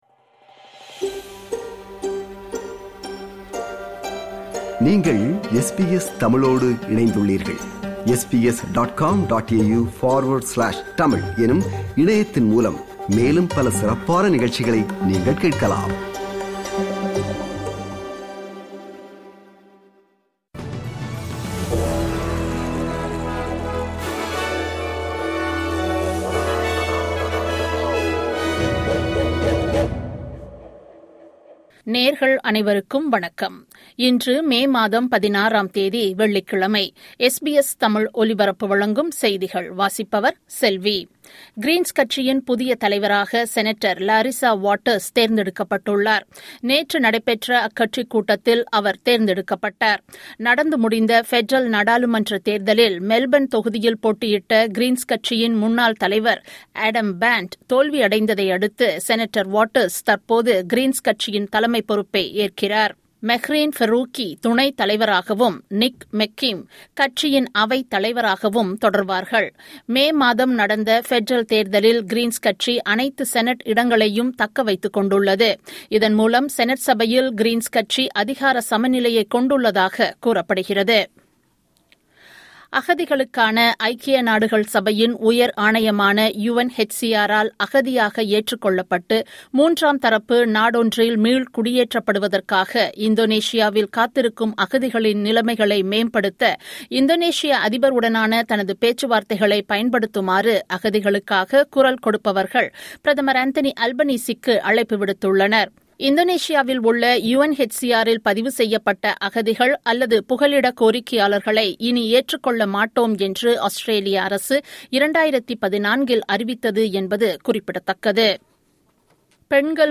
SBS தமிழ் ஒலிபரப்பின் இன்றைய (வெள்ளிக்கிழமை 16/05/2025) செய்திகள்.